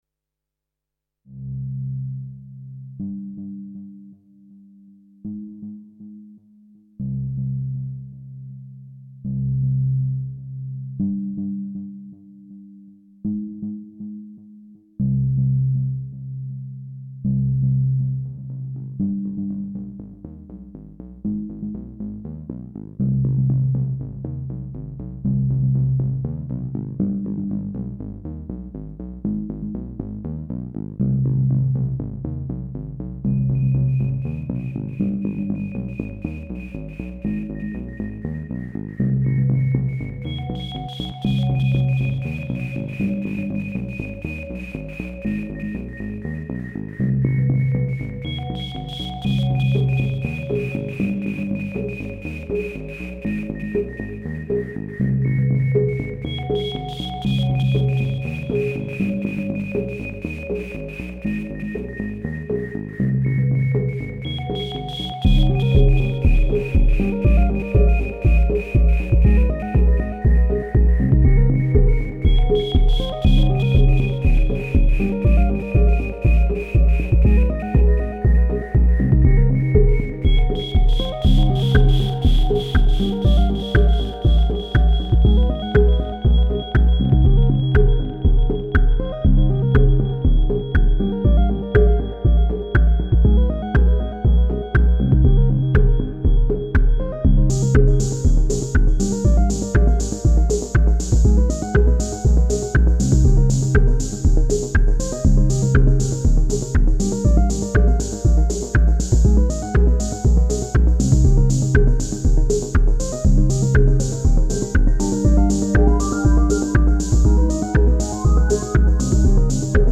I’m a very big fan of the new machines, here’s another little jam i did with them:
Sweet dark little romp
How’d you get that shimmering/icey pad?
Cheers, that should be UT Noise with self oscillating filter, with some LFO modulation.